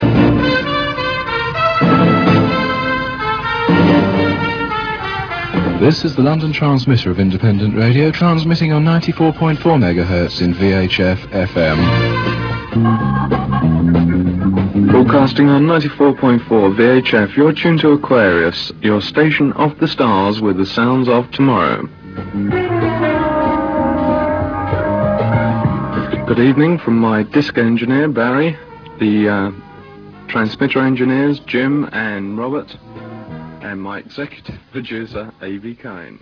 Signing on nightly with the 'Born Free' instrumental theme, some of the stations heard on the L.T.I.R. included Radio Aquarius on Friday nights, Radio London Underground on Sunday nights (which grew out of the London Underground programme on Radio Jackie VHF), Radio Star, Radio Odyssey, Radio Classic and eventually Radio Jackie.